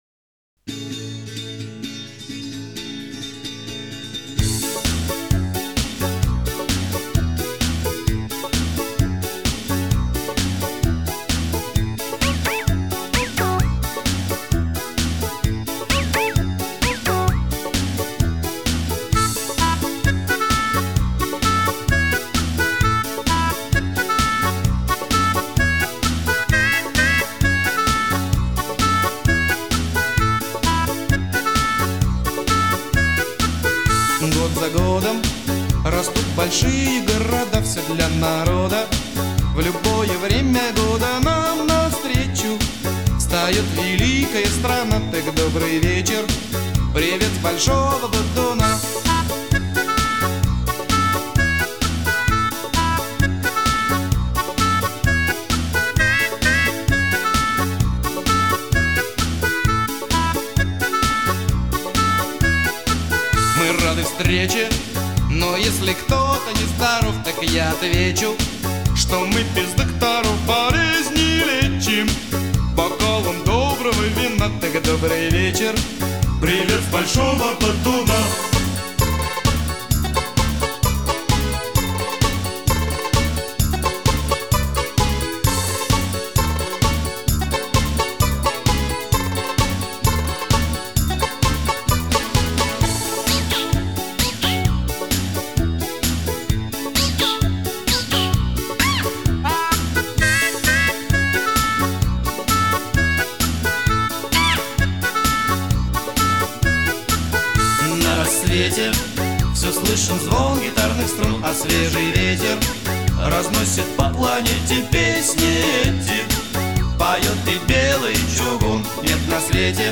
Веселая, красивая, песня типа шансон.